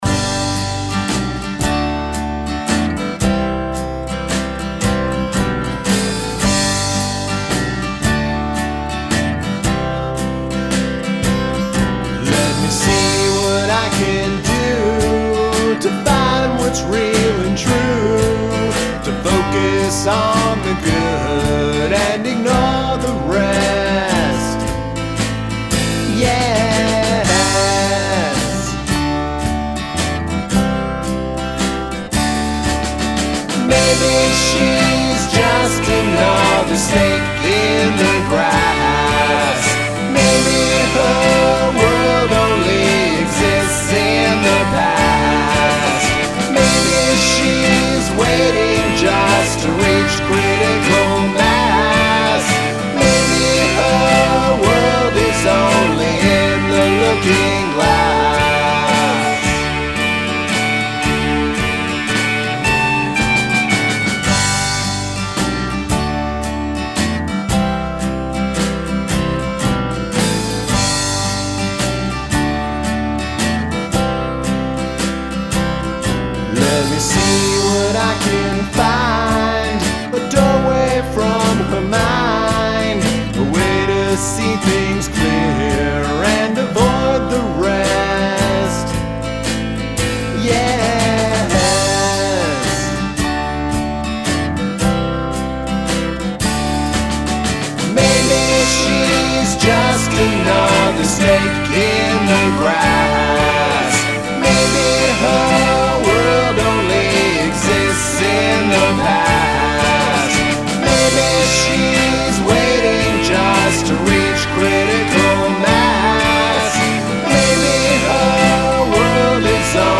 Waltz
Sounds pretty slick.
Some of the guitar was pretty sweet.